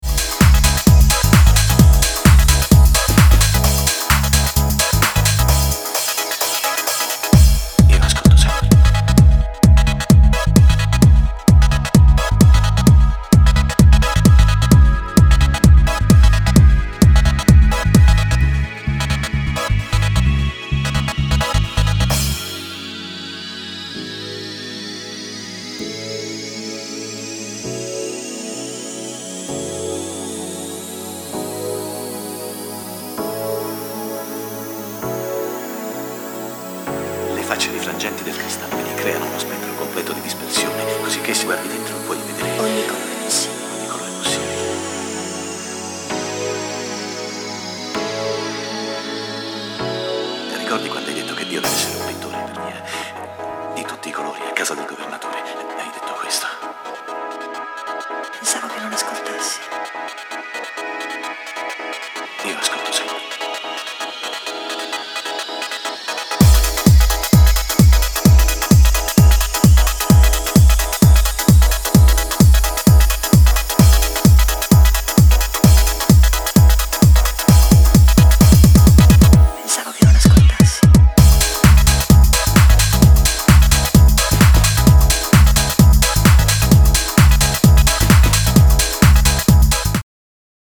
いずれも、90s前半のイタリアン・ハウスの情緒的な部分までもモダンなプロダクションできちんと汲み取った意欲作。